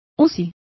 Complete with pronunciation of the translation of ICU.